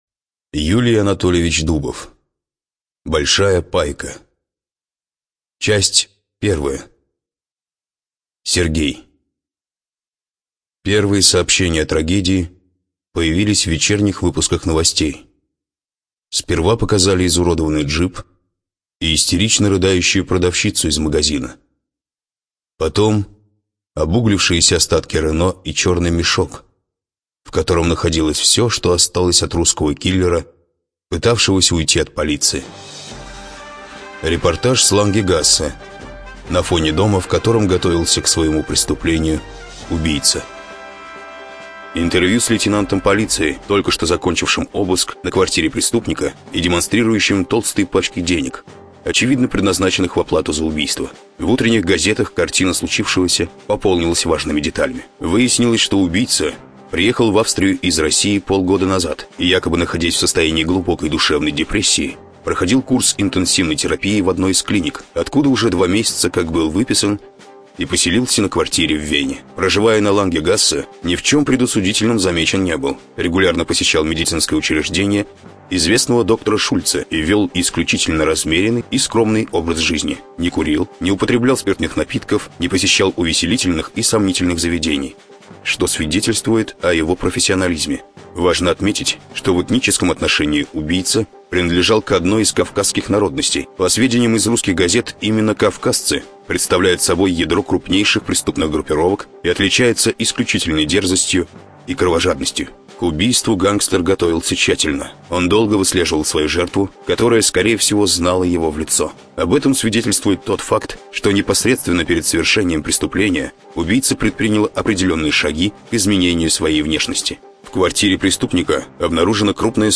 Студия звукозаписиРавновесие